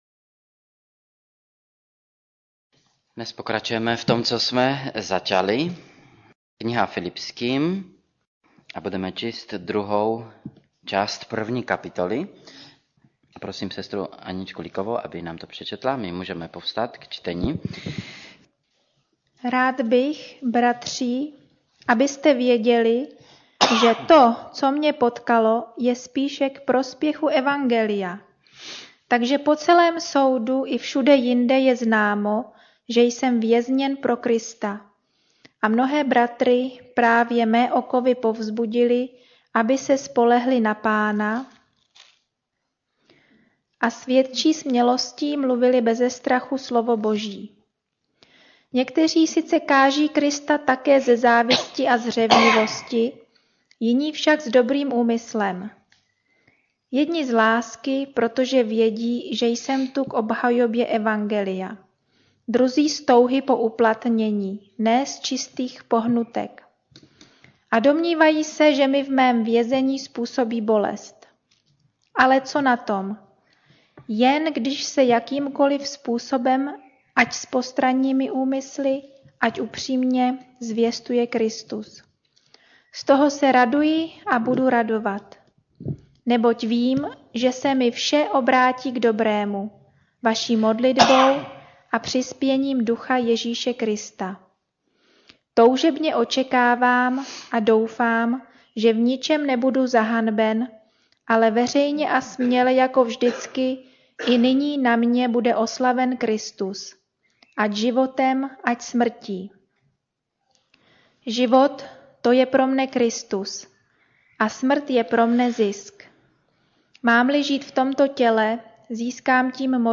Radost nezávislá na okolnostech Kategorie: Kázání MP3 Zobrazení: 3438 Jak žít radostný život - Filipským 1:12-226 2.